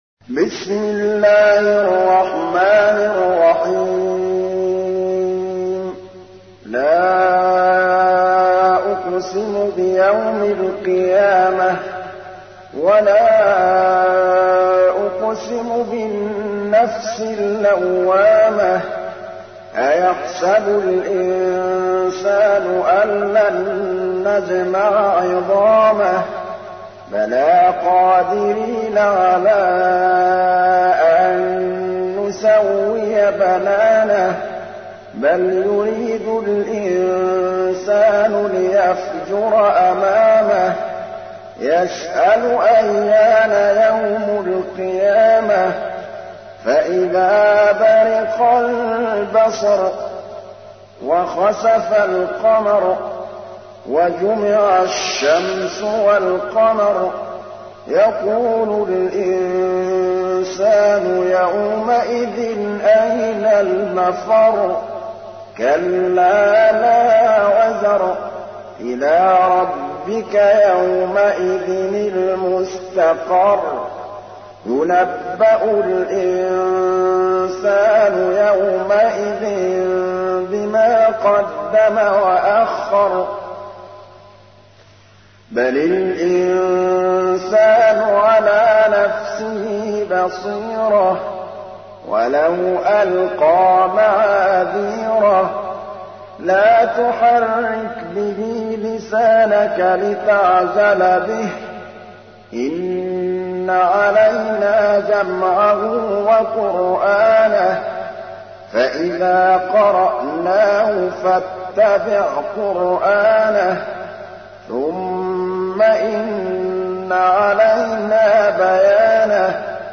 تحميل : 75. سورة القيامة / القارئ محمود الطبلاوي / القرآن الكريم / موقع يا حسين